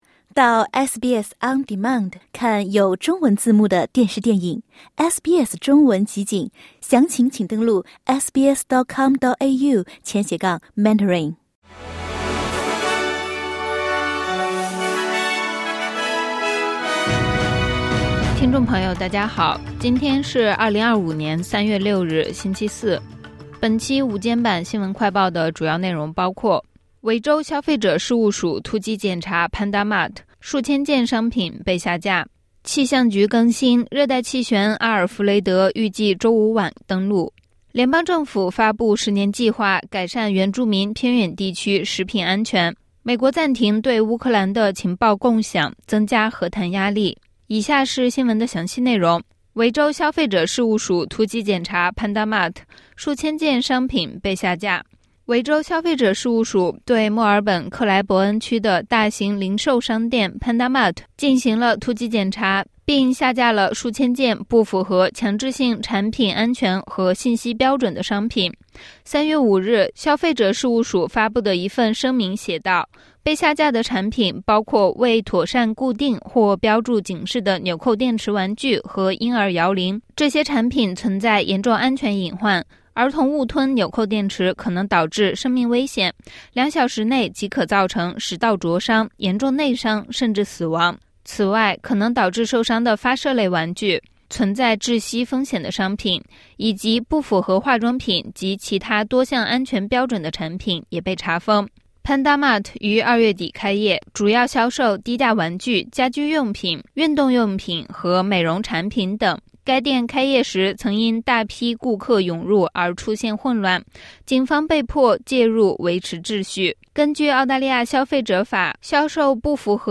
【SBS新闻快报】墨尔本零售新店Panda Mart数千件商品被下架